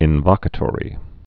(ĭn-vŏkə-tôrē)